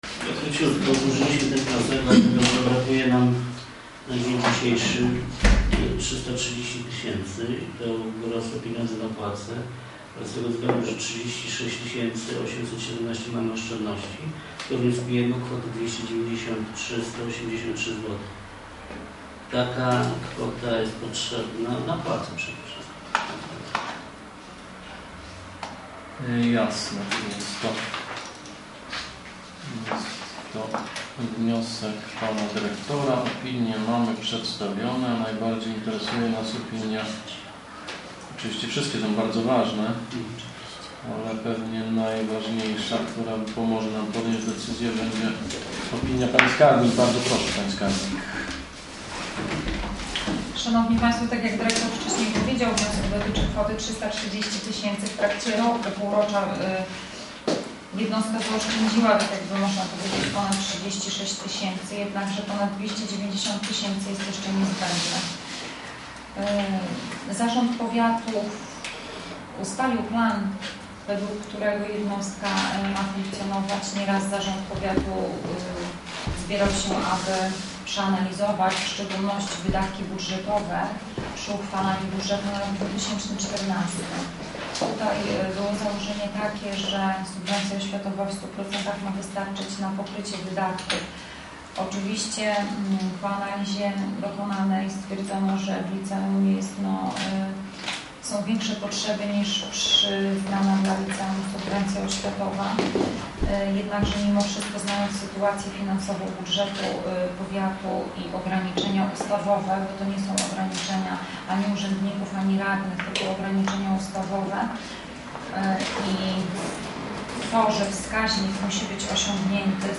obrady zarządu powiatu